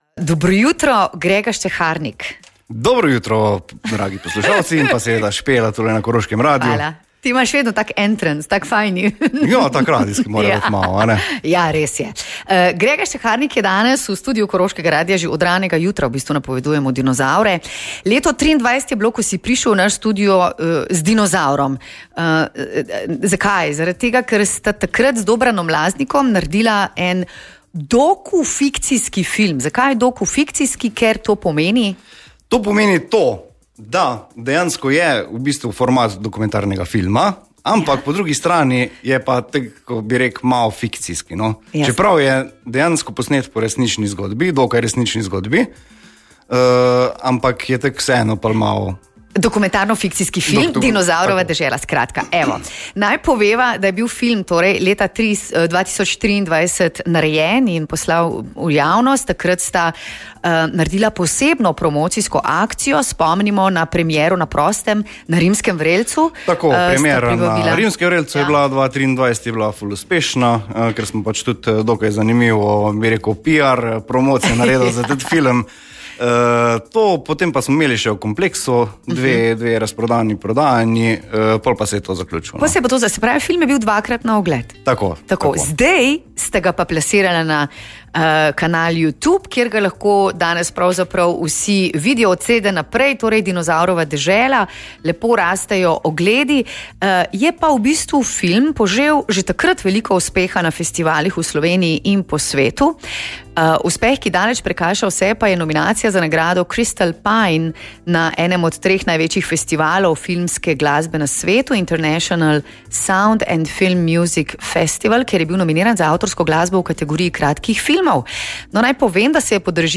Več o filmu in ali prihaja nadaljevanje, slišite v pogovoru.